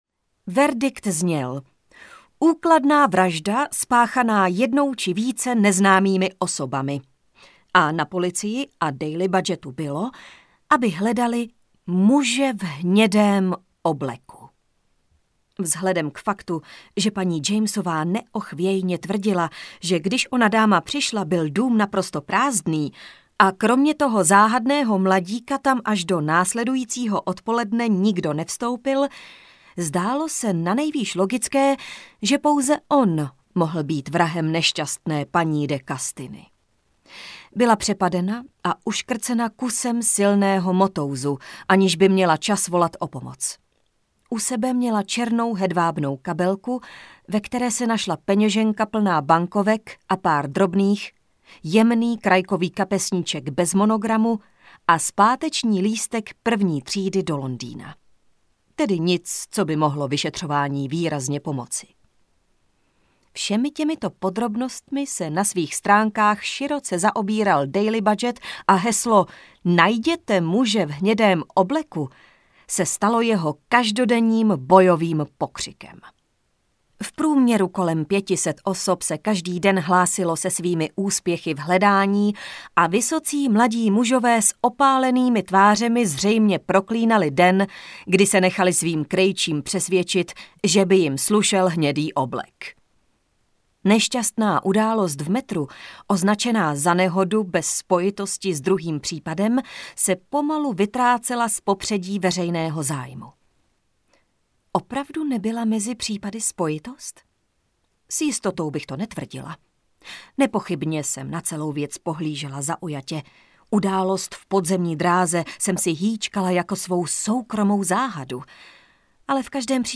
Muž v hnědém obleku audiokniha
Ukázka z knihy